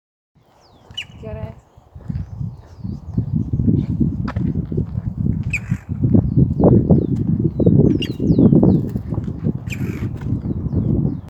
Scientific name: Colaptes melanochloros melanolaimus
English Name: Green-barred Woodpecker
Sex: Both
Life Stage: Adult
Country: Argentina
Province / Department: Entre Ríos
Condition: Wild
Certainty: Observed, Recorded vocal